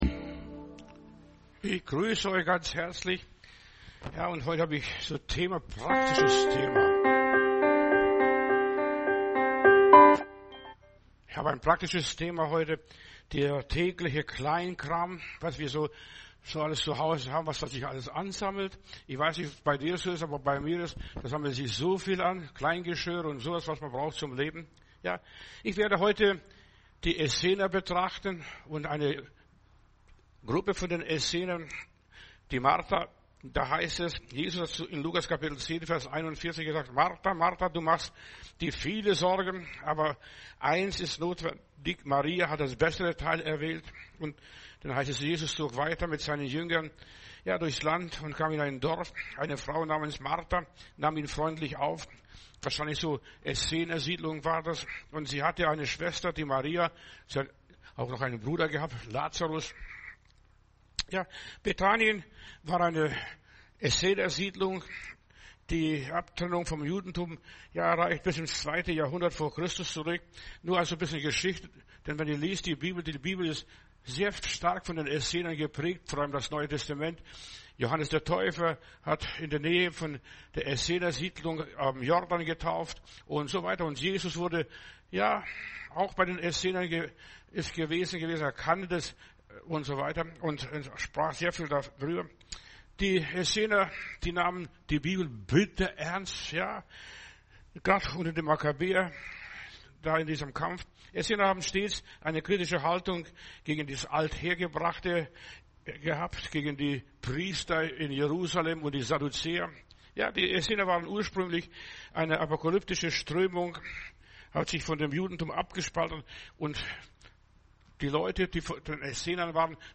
Predigt herunterladen: Audio 2025-01-08 Der tägliche Kleinkram Video Der tägliche Kleinkram